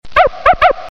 小狗叫声.MP3